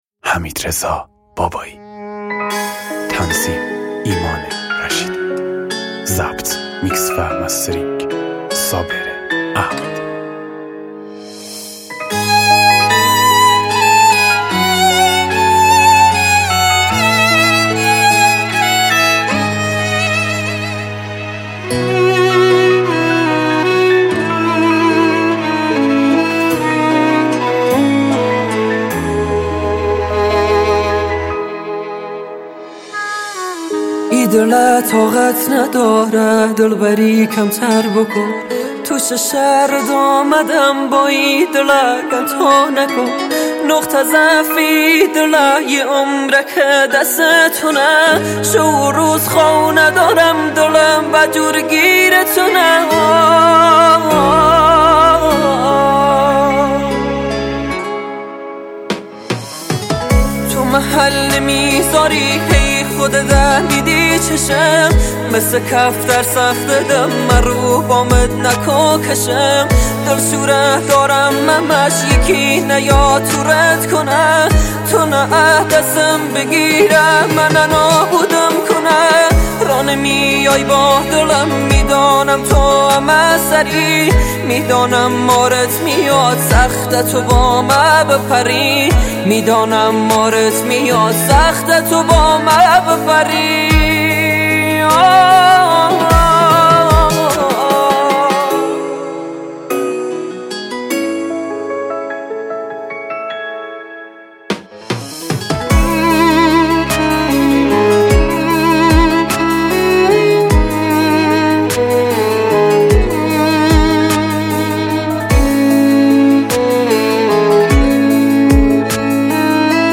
با صدای گرم